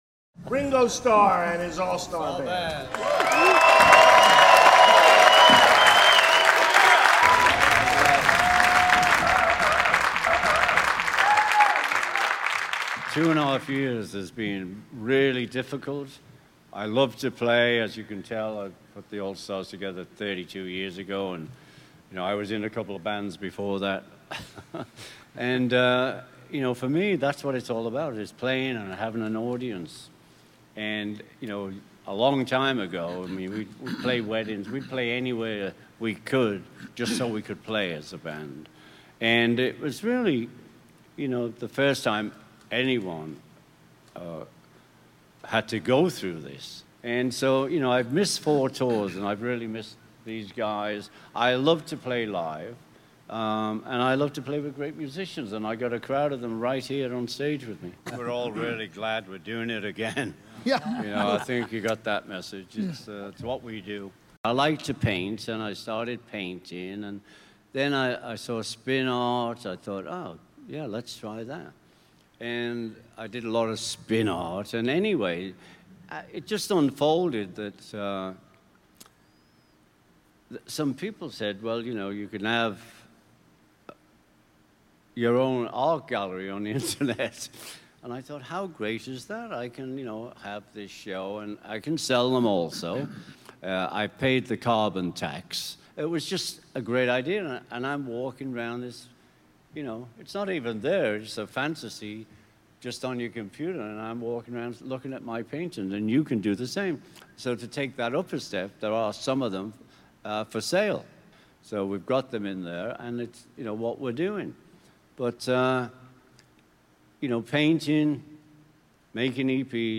The band sat down for a tour launch event at Casino Rama in Rama, Canada.